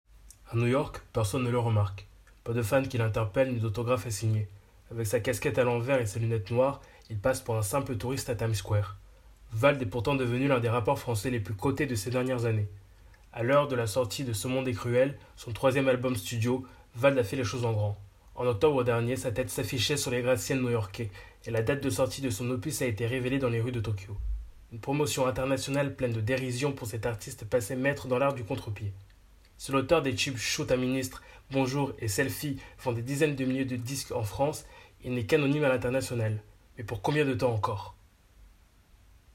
Voix off essai
- Ténor